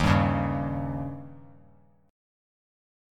Eb7 Chord
Listen to Eb7 strummed